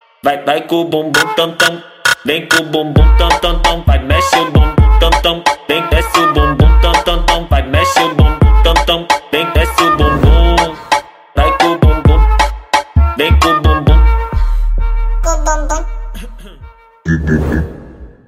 Descarga de Sonidos mp3 Gratis: bum tam.